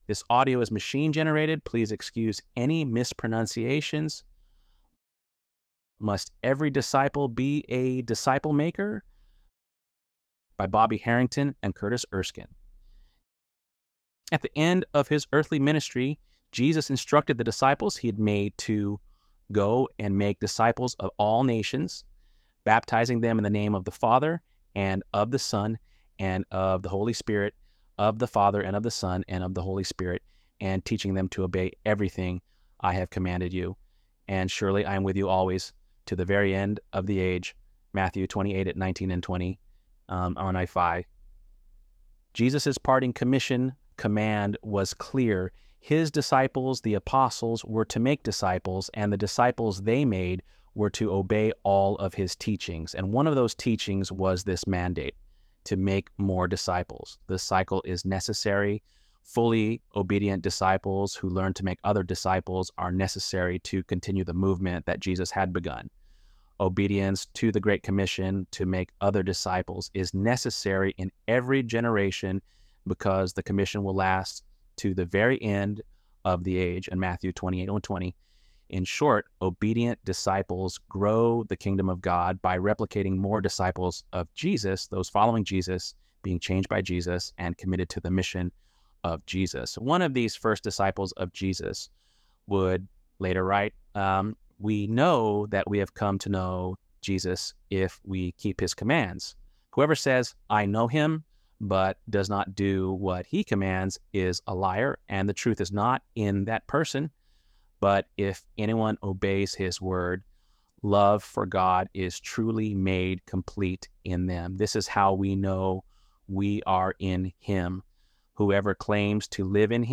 ElevenLabs_10.11.mp3